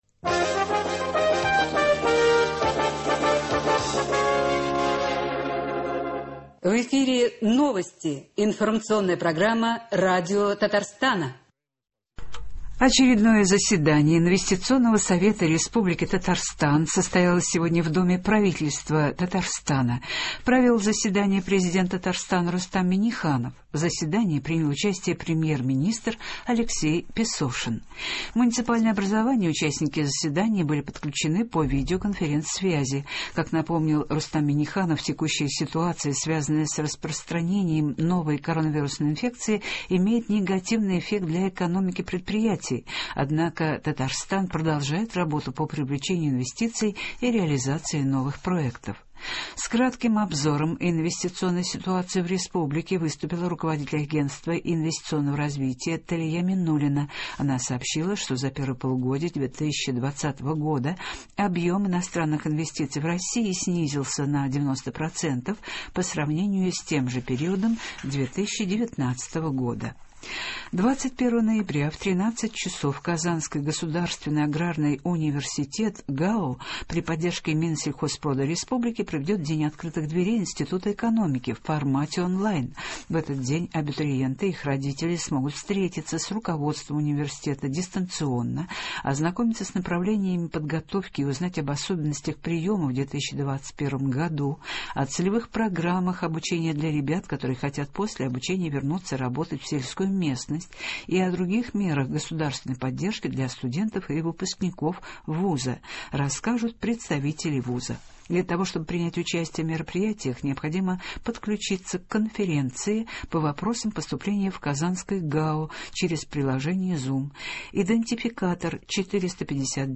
Новости (18.11.20)